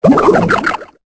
Cri de Méios dans Pokémon Épée et Bouclier.